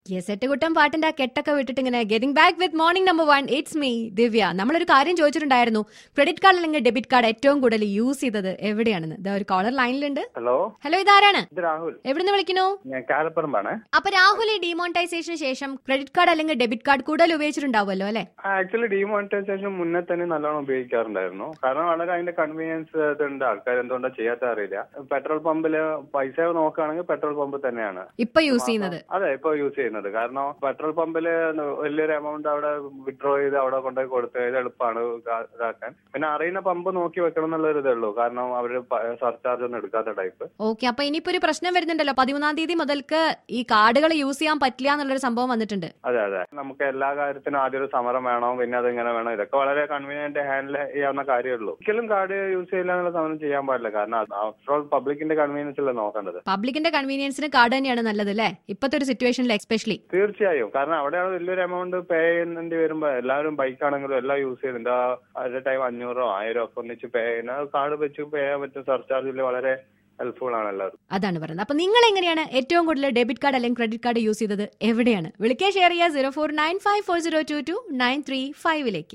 WITH CALLER ON WHERE DID YOU USE CREDIT/DEBIT CARDS THE MOST.